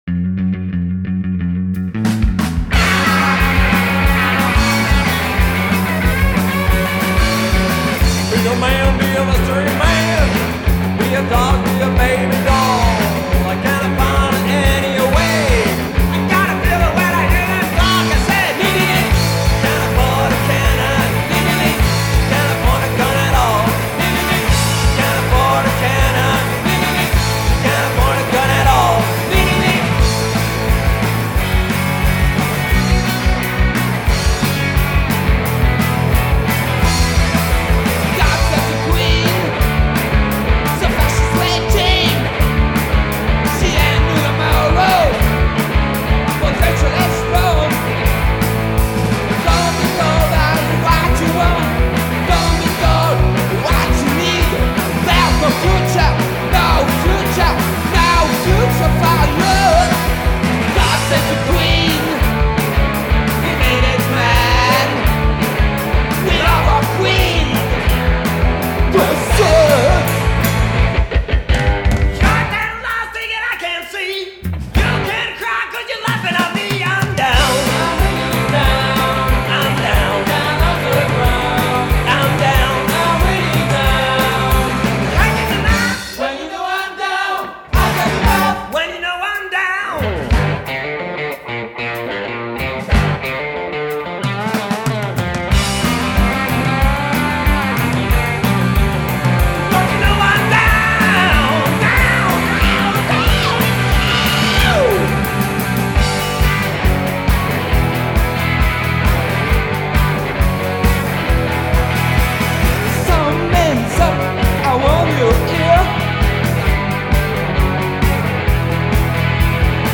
Vespa bass, backing vocals & spiritual leadership
Drum rules
guitar left & backing yellings
main voice & guitar right